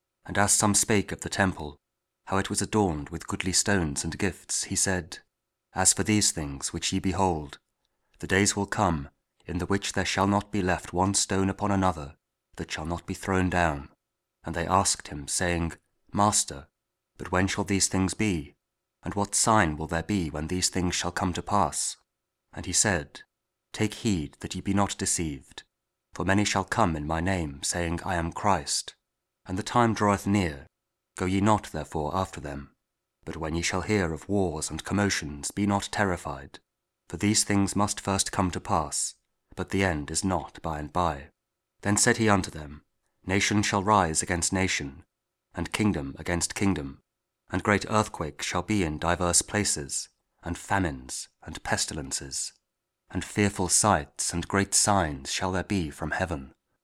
Luke 21: 5-11 – Week 34 Ordinary Time, Tuesday (Audio Bible KJV, Spoken Word)